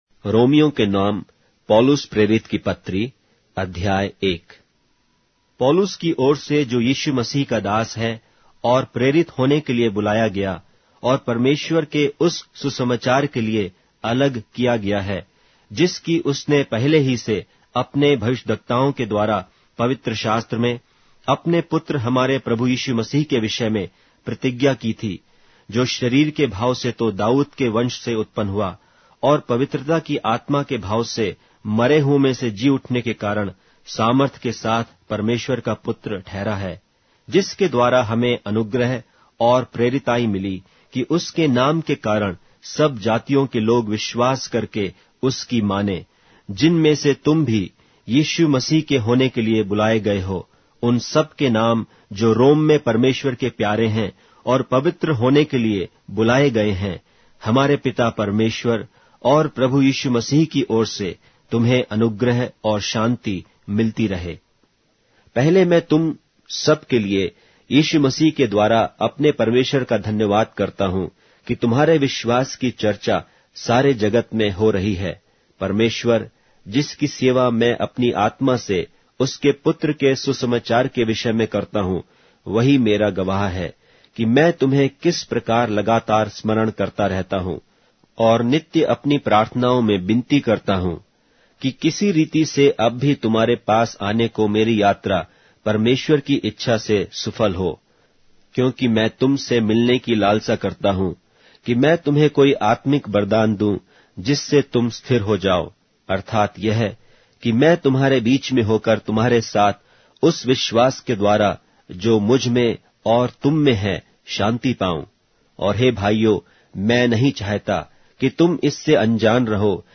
Hindi Audio Bible - Romans 4 in Hov bible version